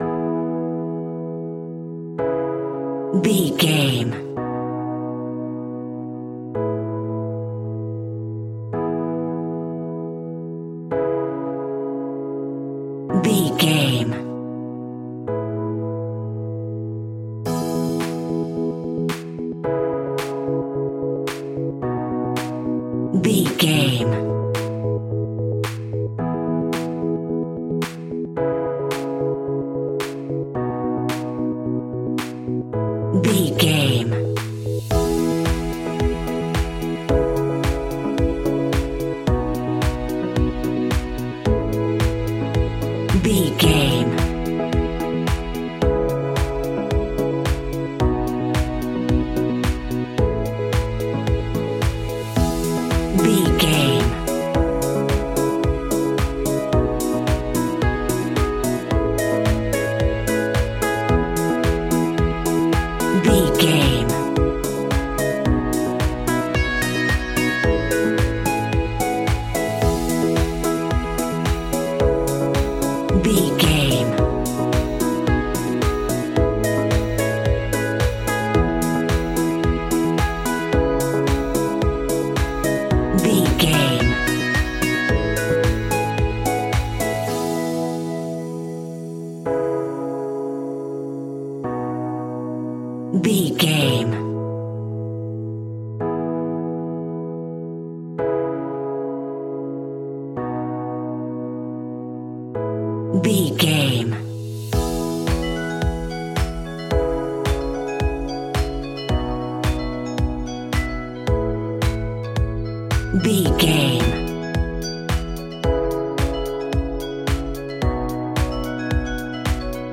Ionian/Major
peaceful
calm
electric piano
synthesiser
drums
strings
funky house
instrumentals